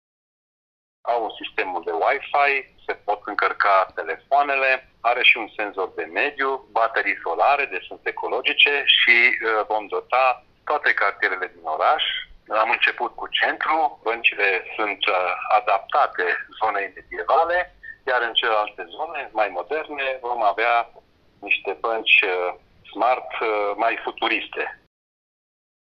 „Este un prim pas spre o comunitate smart. Sunt convins că vor fi atracţie pentru cetăţenii oraşului”, a declarat primarul Ghimbavului, Ionel Fliundra: